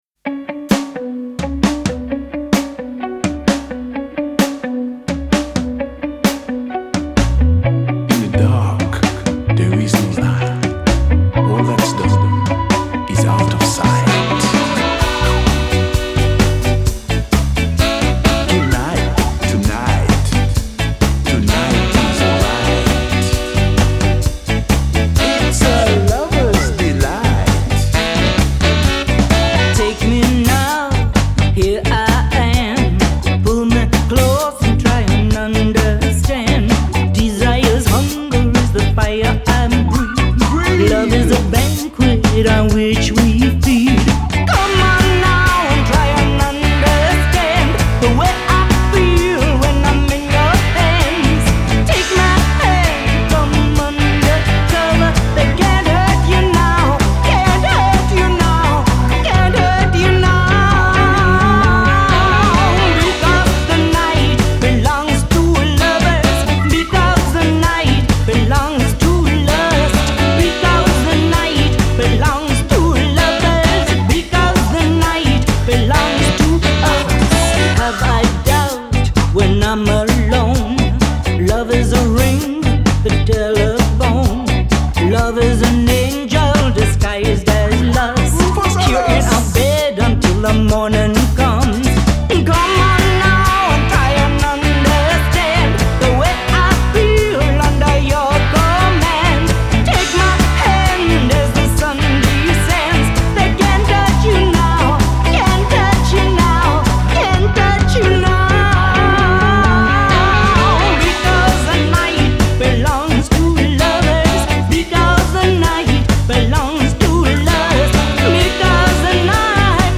Genre: Ska, Reggae, Dub